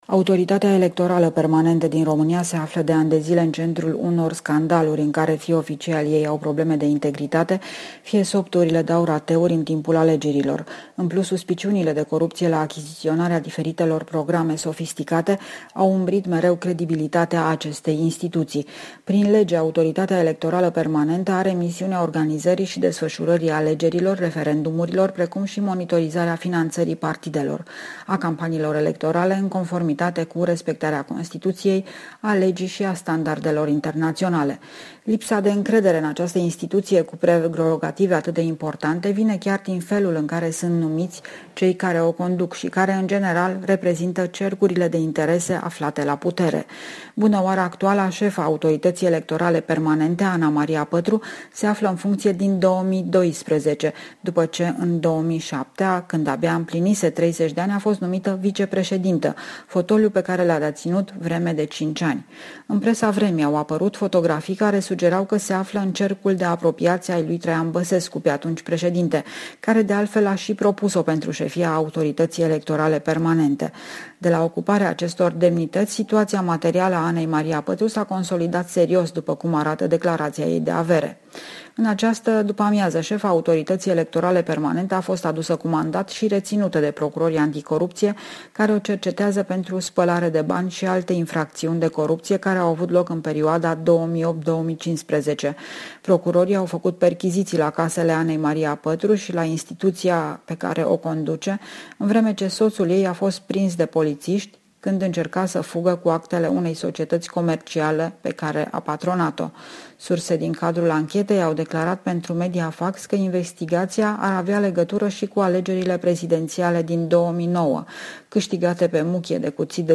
Corespondenţa zilei de la Bucureşti